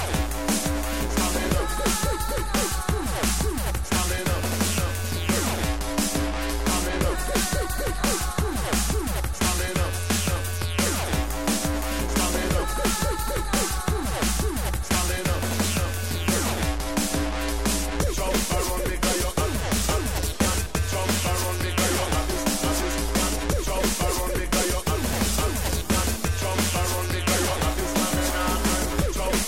TOP >Vinyl >Drum & Bass / Jungle
TOP > Vocal Track
TOP > Jump Up / Drum Step